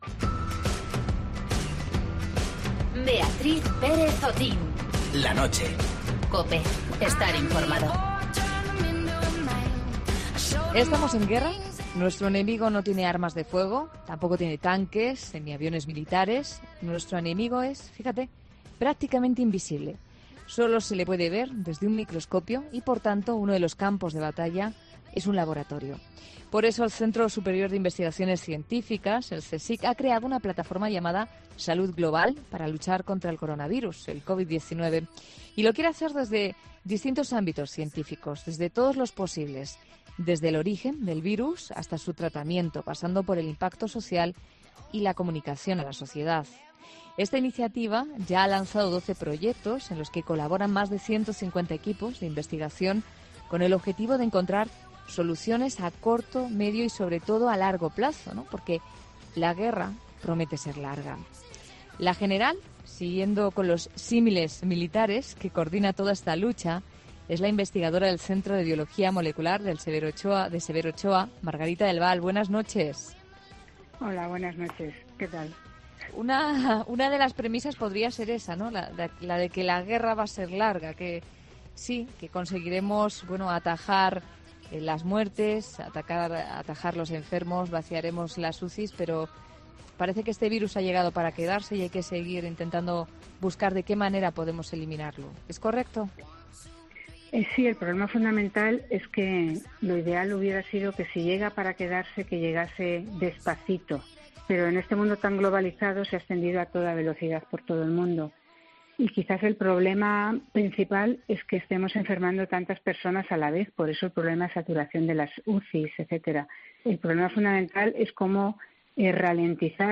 La investigadora Margarita del Val habla sobre la plataforma puesta en marcha por el CSIC para combatir el coronavirus